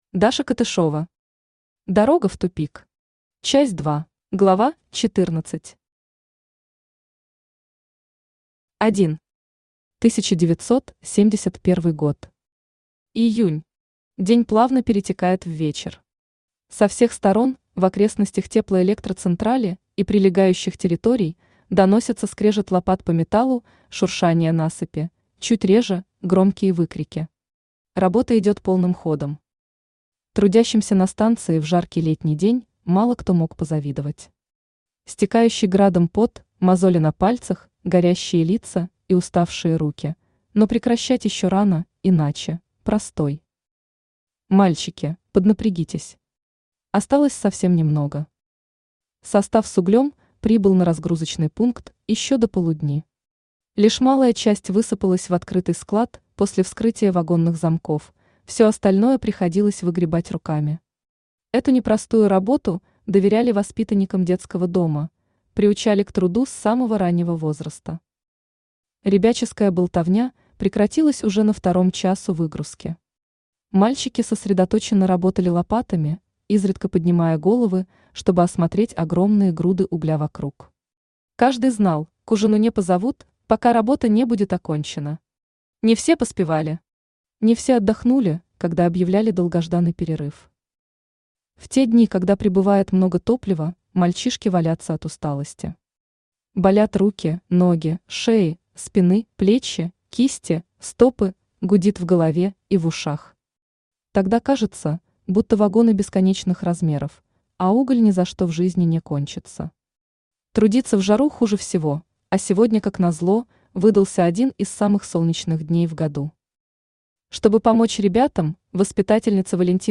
Аудиокнига Дорога в тупик.
Часть 2 Автор Даша Катышева Читает аудиокнигу Авточтец ЛитРес.